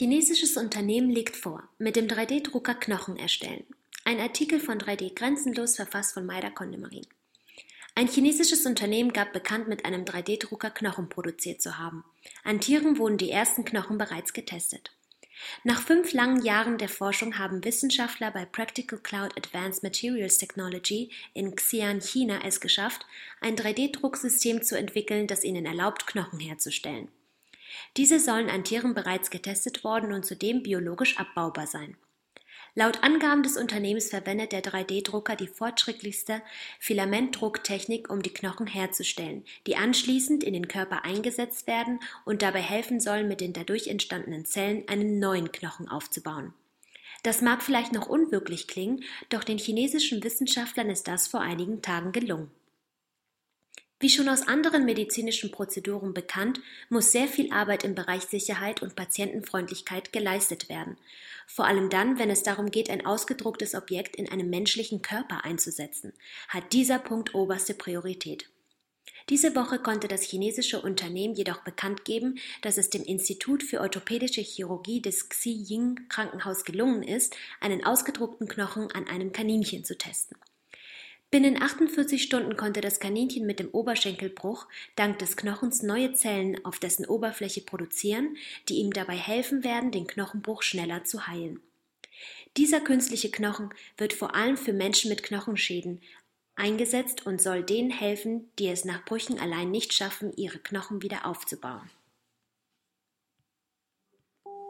news_vorlesen_lassen_mit_dem_3d-drucker_knochen_erstellen.mp3